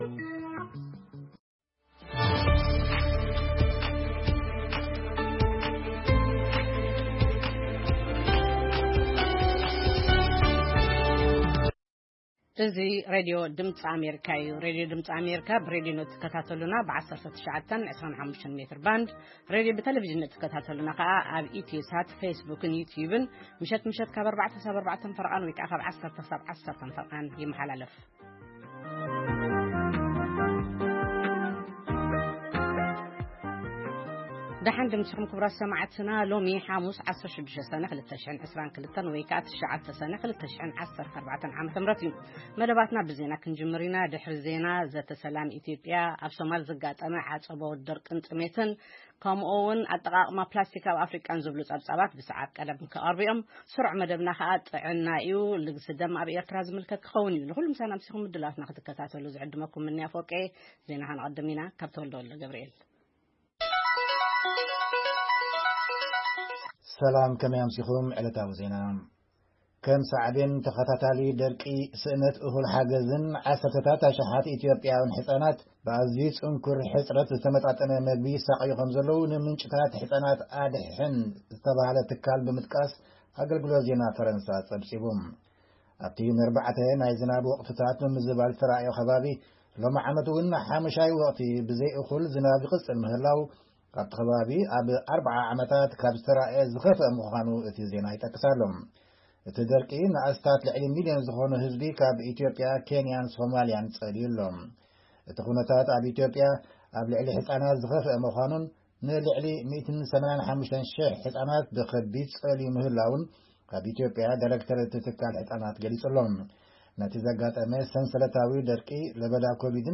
ፈነወ ድምጺ ኣመሪካ ቋንቋ ትግርኛ ሰነ 16,2022